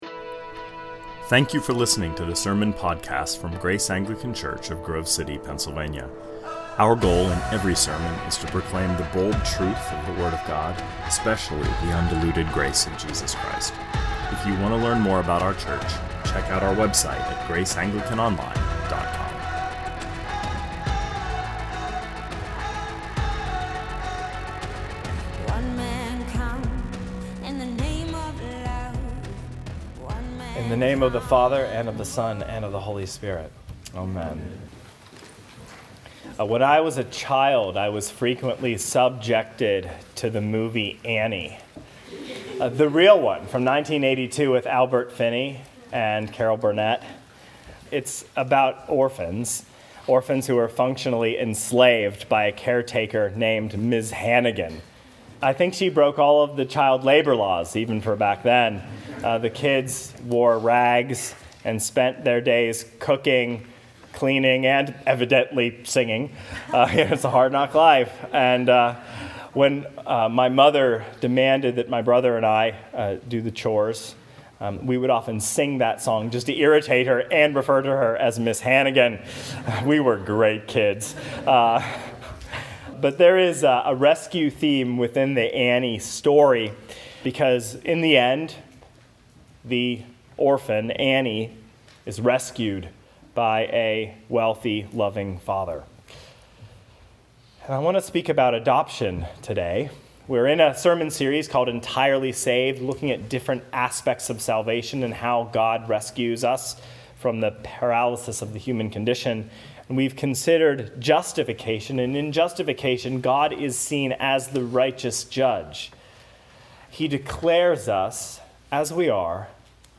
2019 Sermons (Entirely) Saved - Adoption -Galatians 4 Play Episode Pause Episode Mute/Unmute Episode Rewind 10 Seconds 1x Fast Forward 30 seconds 00:00 / 27:25 Subscribe Share RSS Feed Share Link Embed